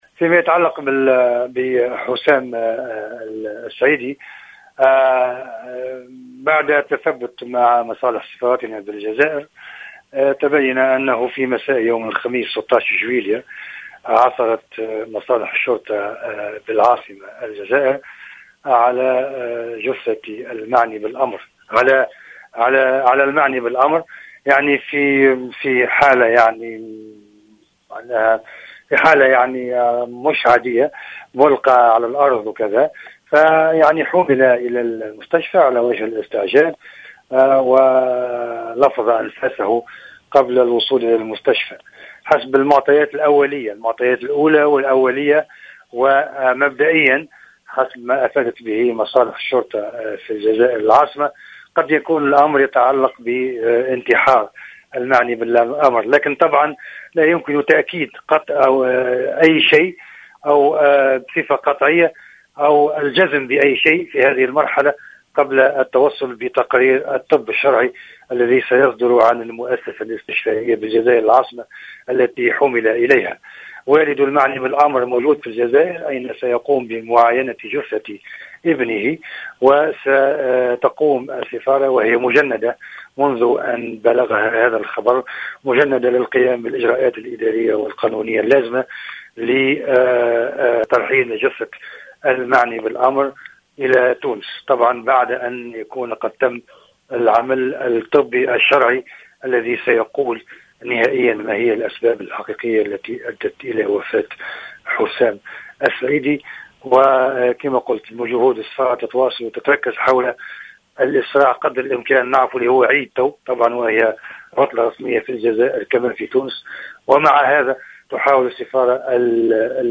déclaration accordée à Jawhara FM